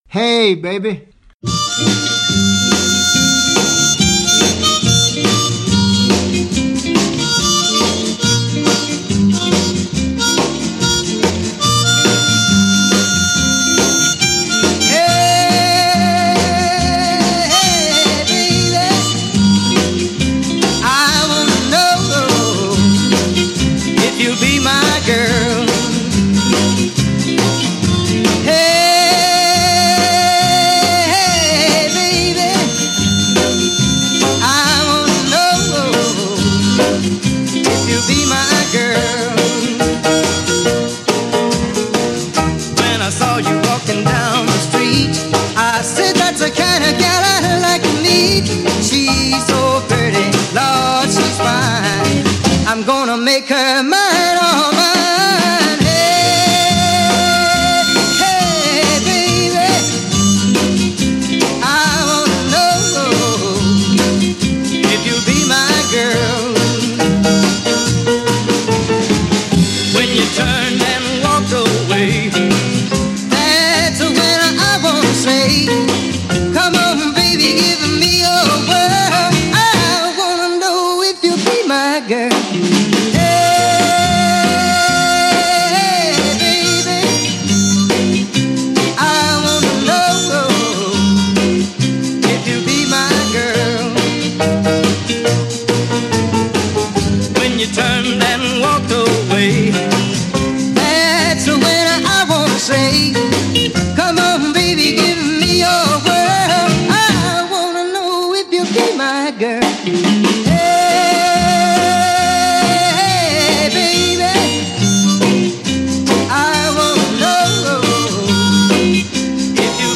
In my interview you will hear Bruce talking about the early years of 1962 when the Beatles were the opening band for Bruce Channel. You will also hear about the famous harmonica of Delbert McClinton teaching John Lennon and you will hear some amazing stories on Hey Baby, Bruce’s number 1 hit recorded over 200 times with various artists including Ringo Starr.
bruce-channel-interview-blog.mp3